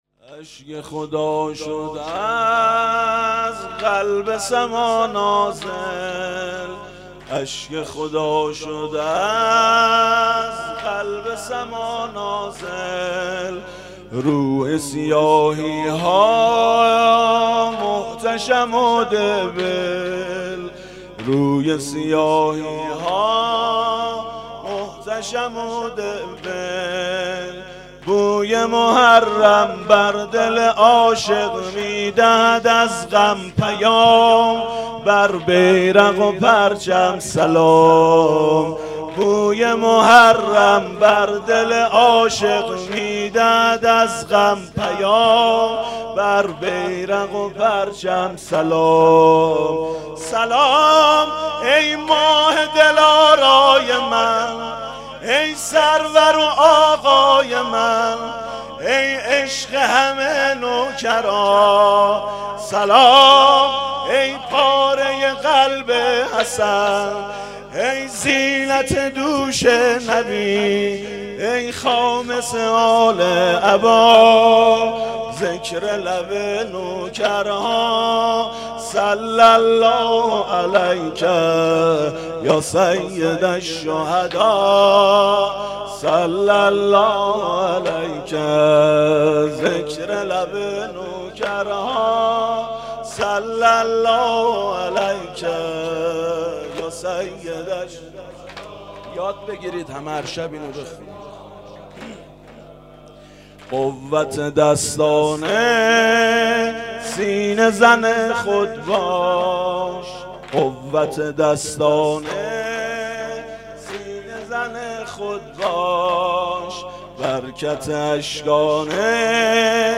حسینیه بنی فاطمه(س)بیت الشهدا
محرم 97